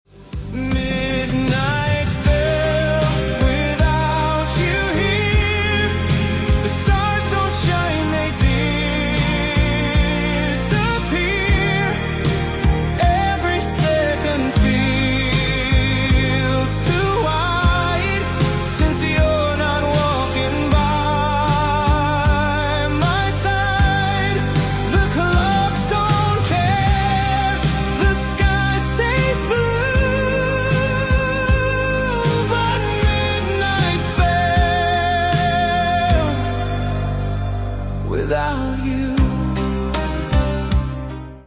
Рок рингтоны
красивый рок рингтон